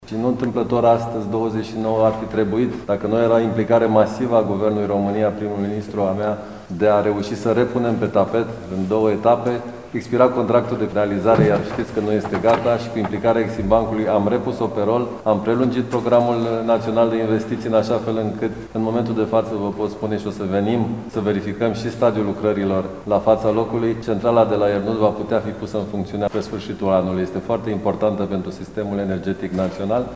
Noua centrală termoelectrică a SNGN Romgaz de la Iernut va fi pusă în funcţiune până la sfârşitul acestui an, a anunţat astăzi la Tîrgu Mureş, Ministrul Economiei, Virgil Popescu: